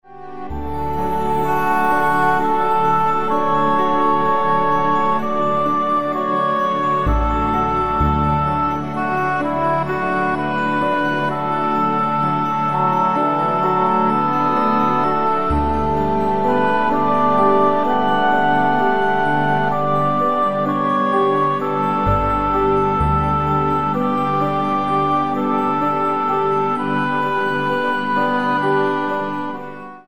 64 BPM